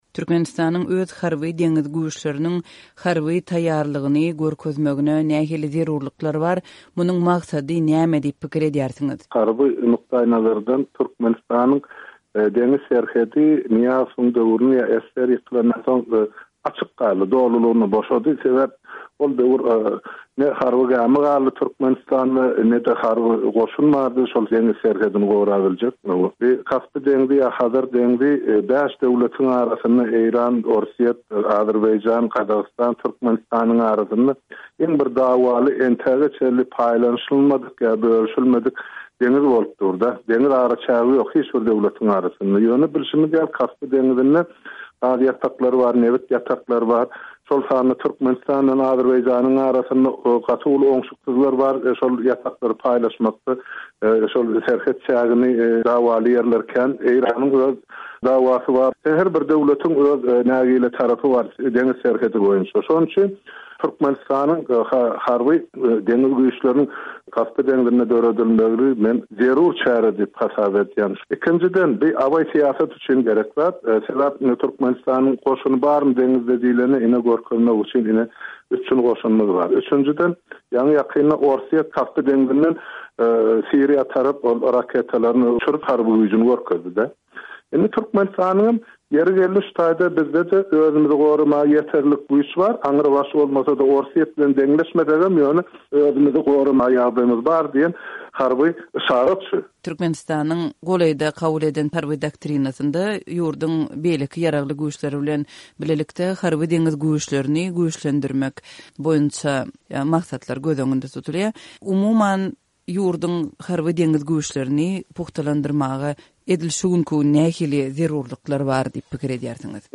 Azatlyk Radiosy Türkmenistanyň ýaragly güýçlerinde 12 ýyllap gulluk eden, türkmen parlamentinde harby meseleler boýunça komissiýa ýolbaşçylyk eden öňki deputat Halmyrat Söýünow bilen söhbetdeş boly.